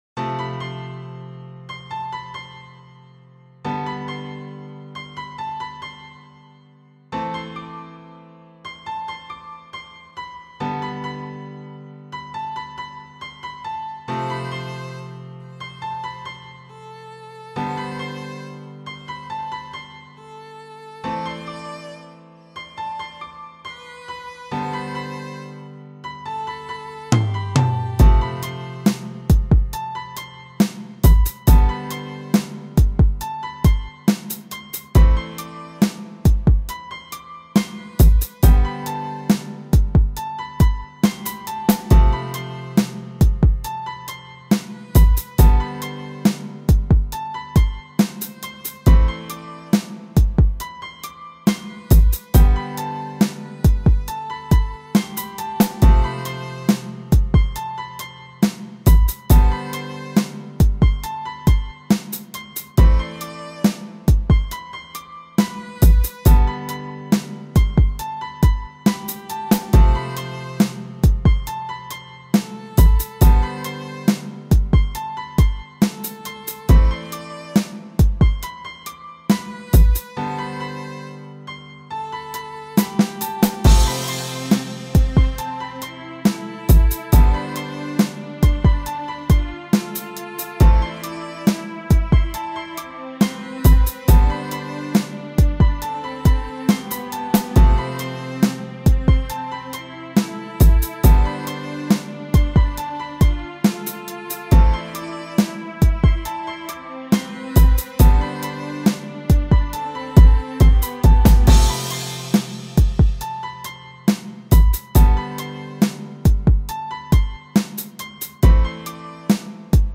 Música – ouvir online e acessar o arquivo